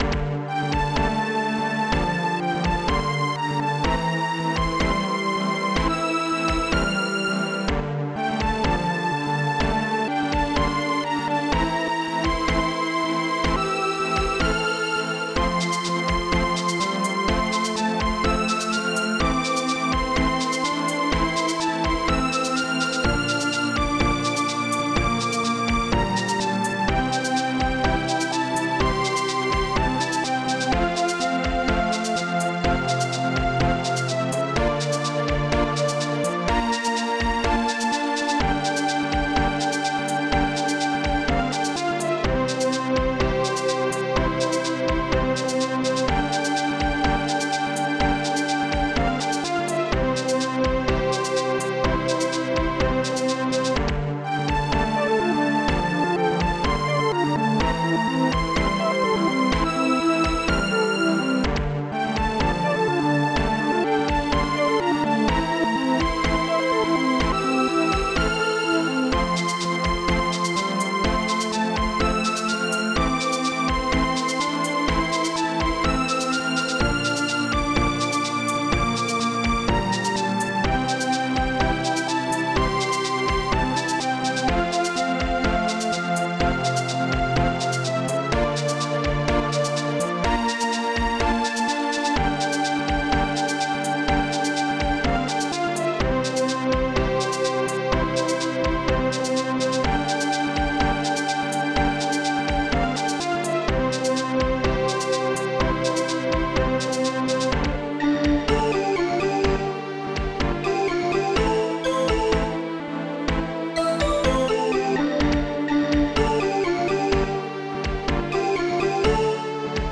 st-04:bass11 st-04:strings2maj st-04:strings22min st-01:strings3 st-01:strings7 st-01:shaker